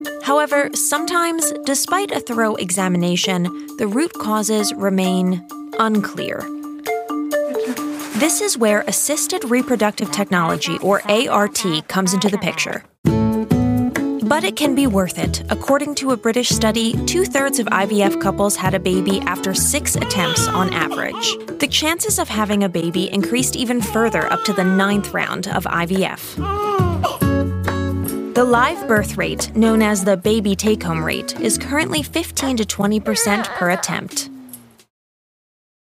hell, fein, zart, sehr variabel
Jung (18-30)
Doku, Narrative, Presentation